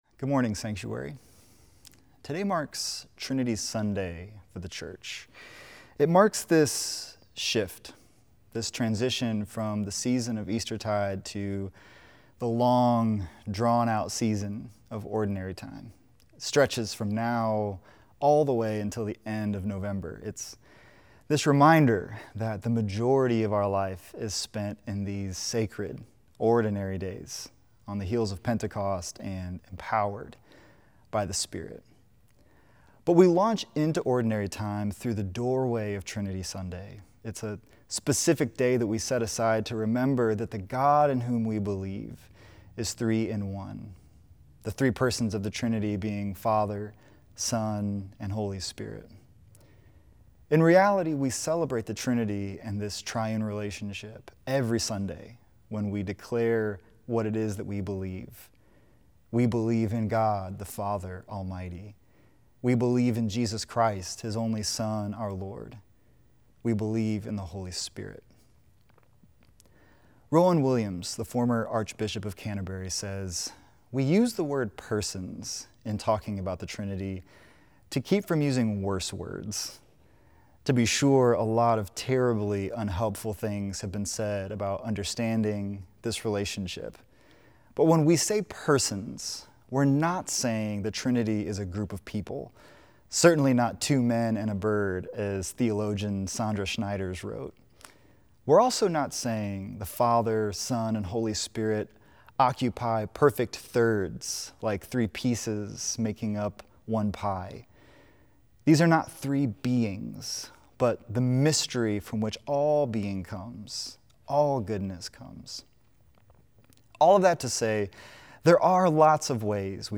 Weekly sermon podcasts from Sanctuary Church in Tulsa, OK